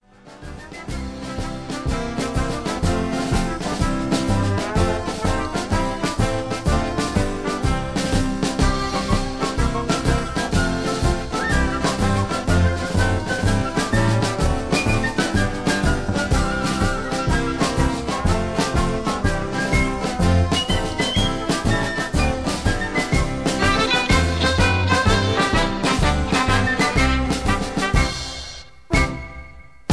(Key-C)
Tags: backing tracks , irish songs , karaoke , sound tracks